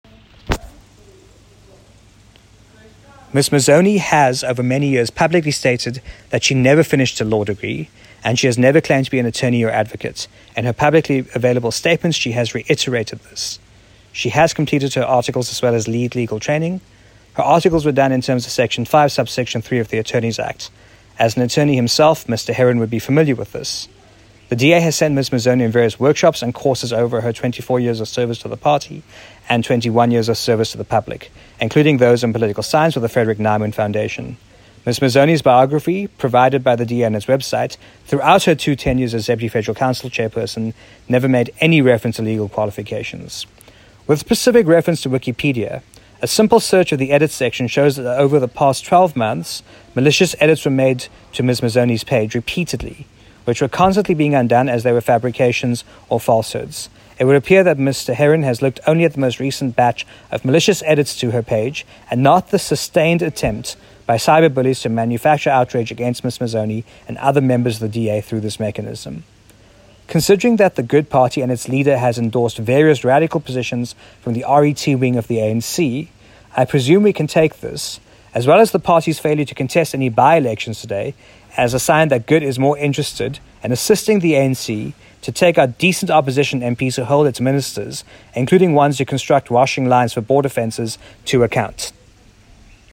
soundbite by the DA Deputy Chairperson of the Federal Council, Ashor Sarupen MP.